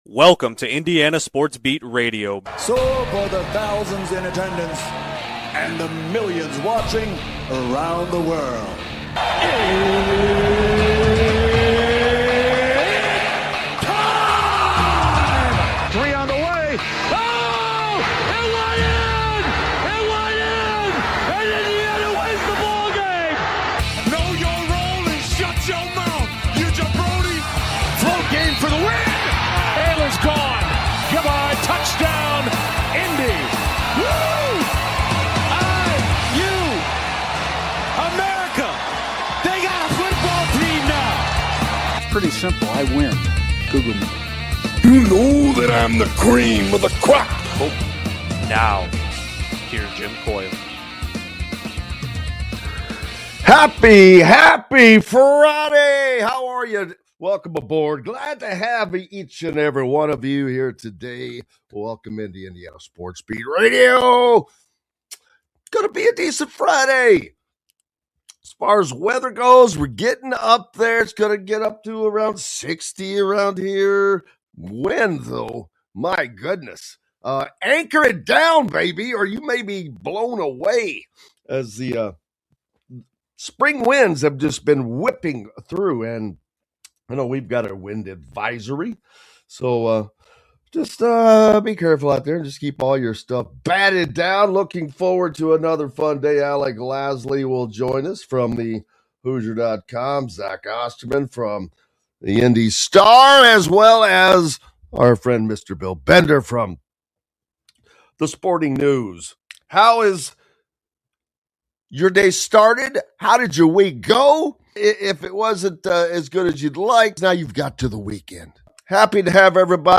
a daily sports talk show covering everything in the sports world with IU, Purdue, Big Ten, Colts, Pacers and IHSAA as a focal point.